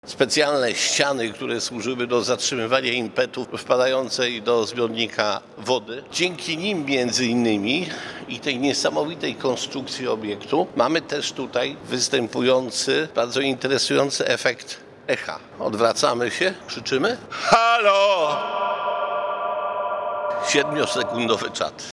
Tu usłyszysz siedmiosekundowe echo.